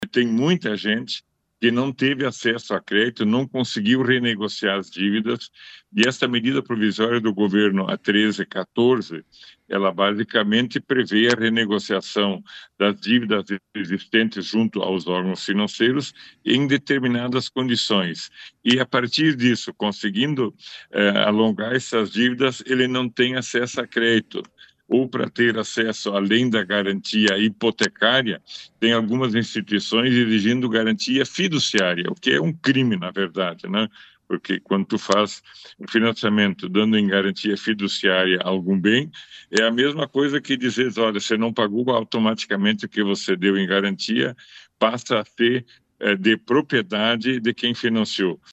durante entrevista no programa Progresso Rural da RPI